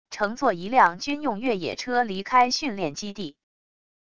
乘坐一辆军用越野车离开训练基地wav音频